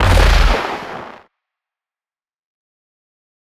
Toon ground explosion.wav